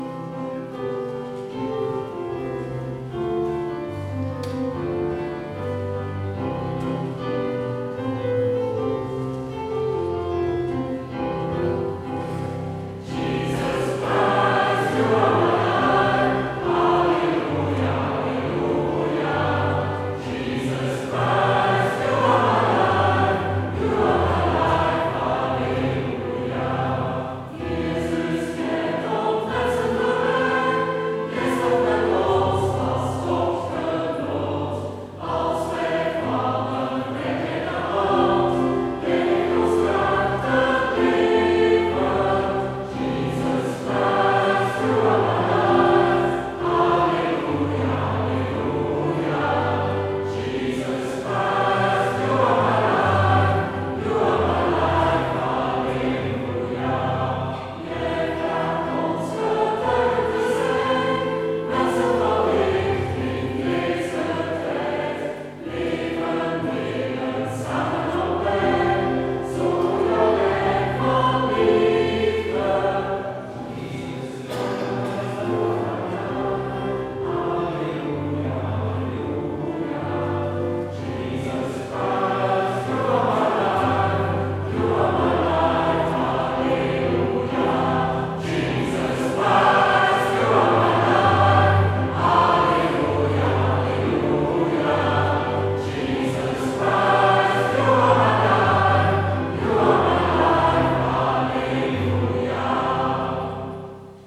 Heropening Sint-Pieterskerk Rotselaar